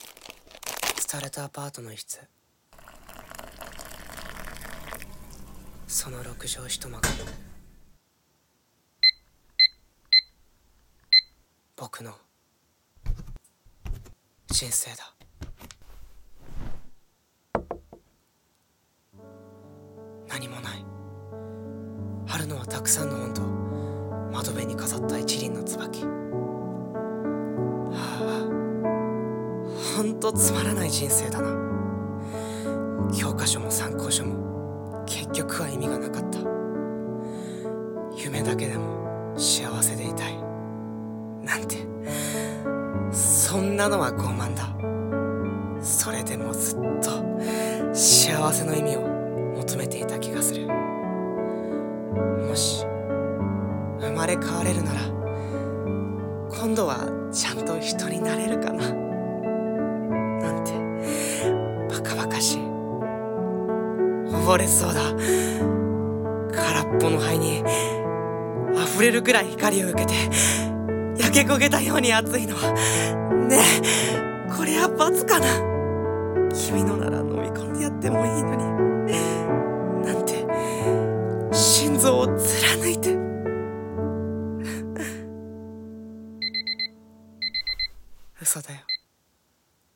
【1人声劇】ヘヴン